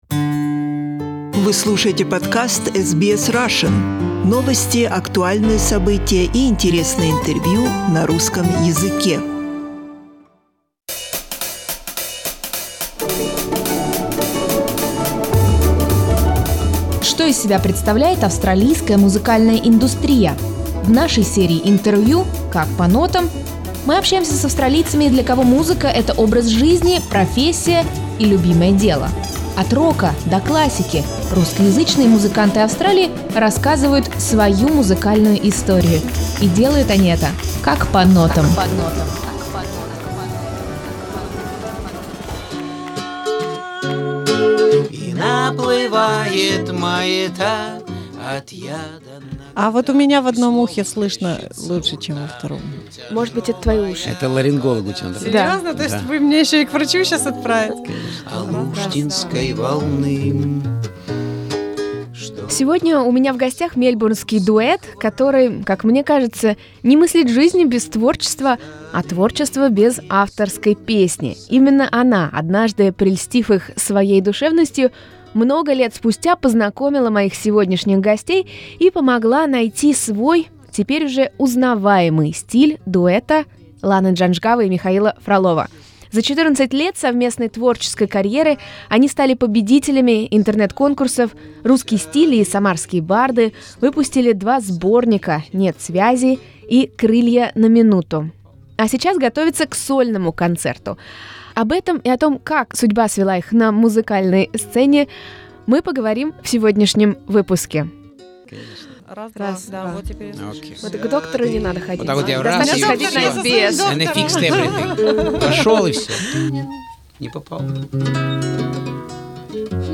Наши гости сегодня - мельбурнский дуэт